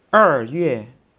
(Click on any Chinese character to hear it pronounced.
eryue.wav